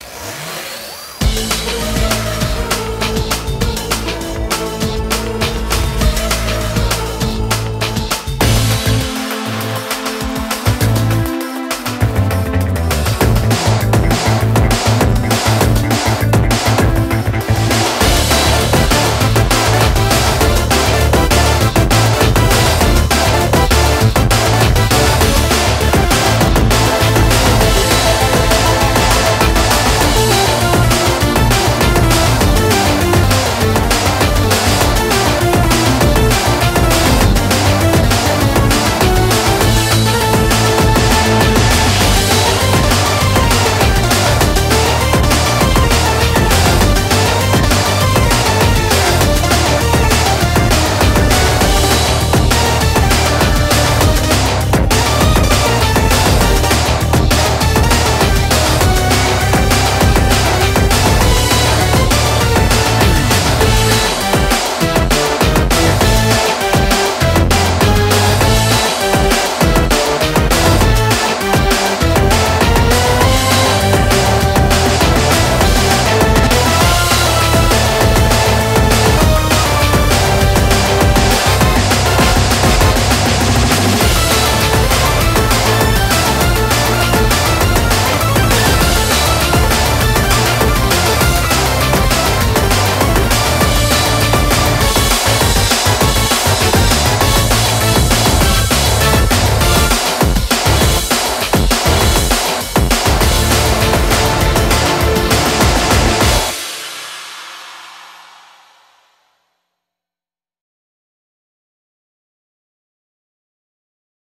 BPM200
Comments[CYBER DRUM AND BASS]